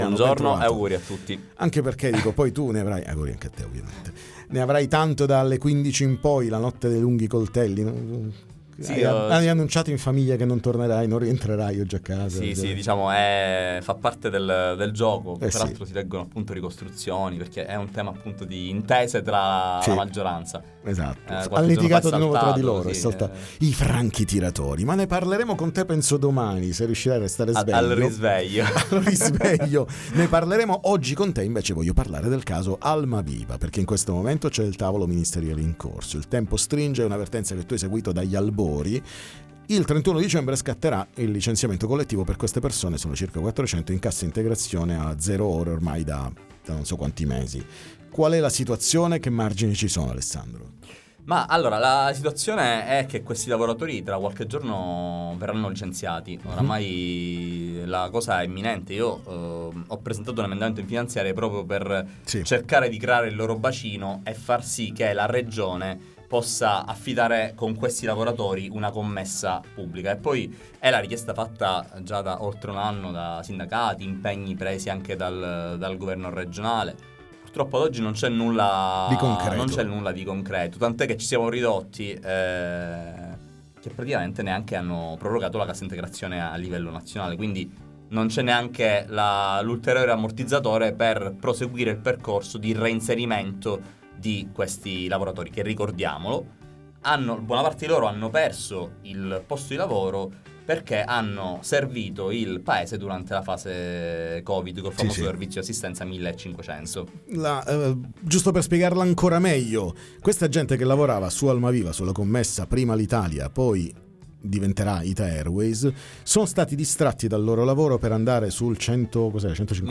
Almaviva: la situazione attuale, ne parliamo con Adriano Varrica, dep. ARS M5S